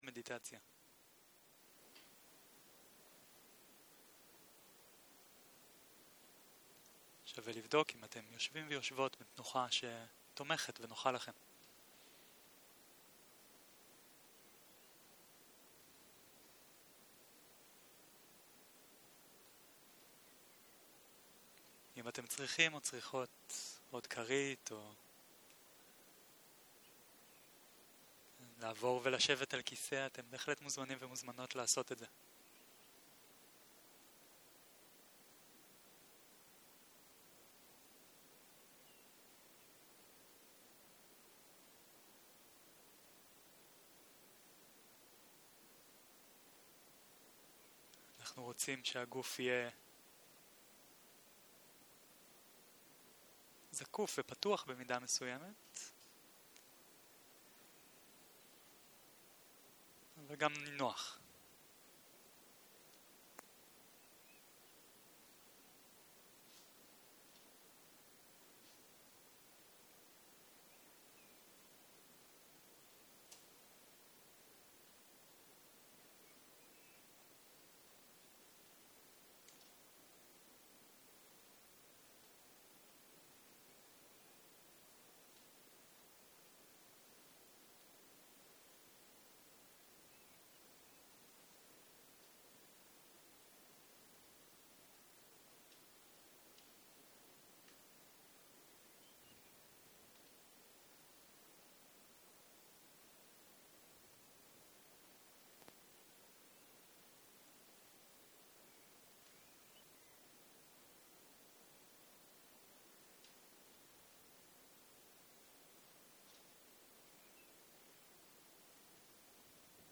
סוג ההקלטה: שיחת הנחיות למדיטציה